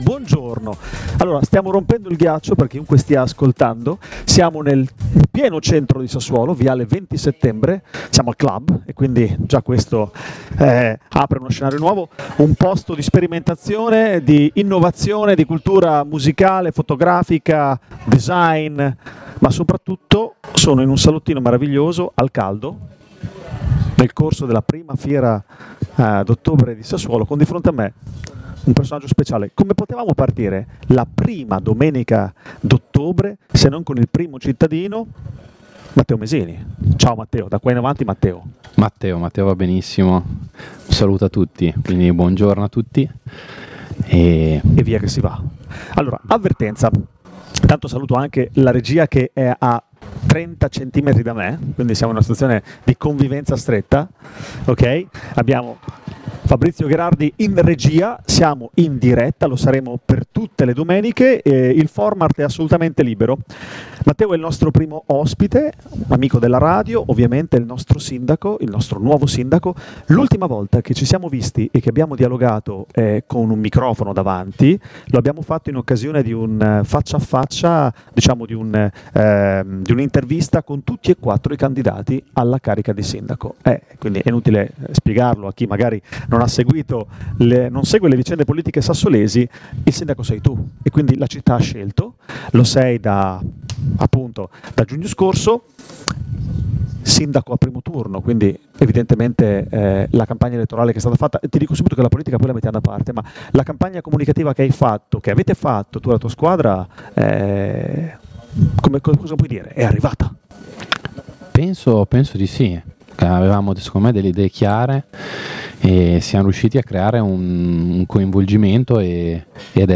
Il sindaco Matteo Mesini – Le interviste di Linea Radio al Clhub di viale XX Settembre a Sassuolo